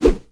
handswing3.ogg